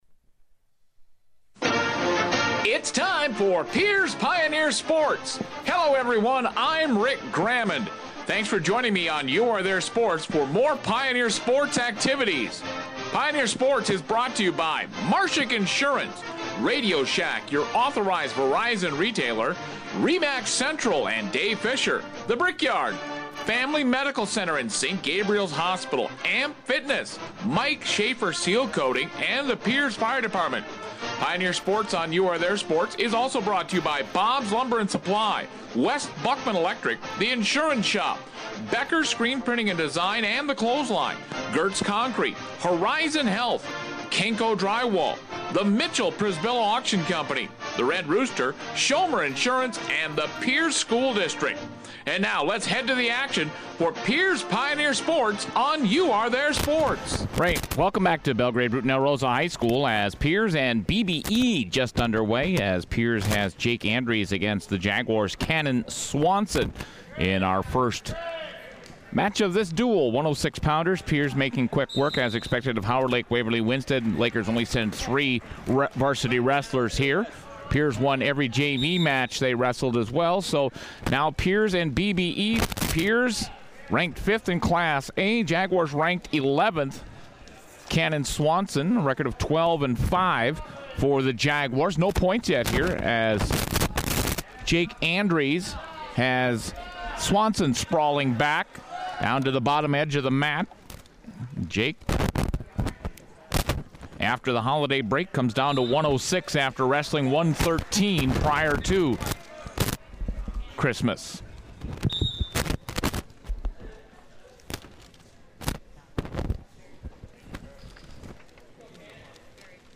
The Pioneers beat the Jaguars 38-21 in a key CMC match at the BBE Triangular.